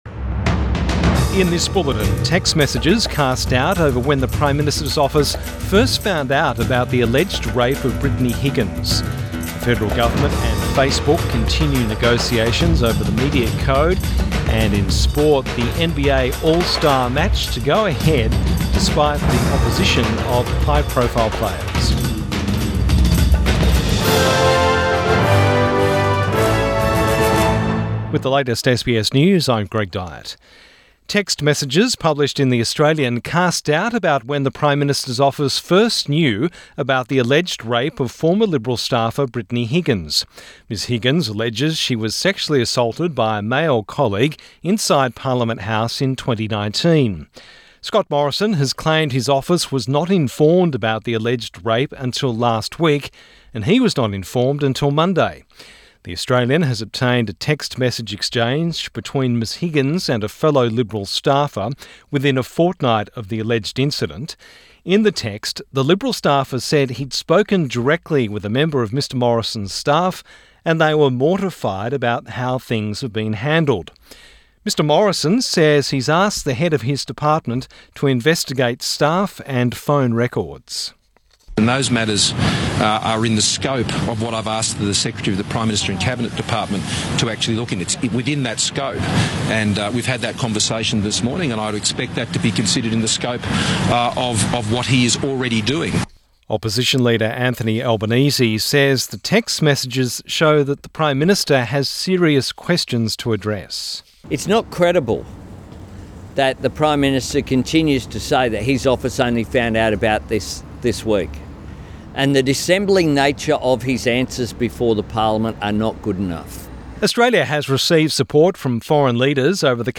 Midday bulletin 19 February 2021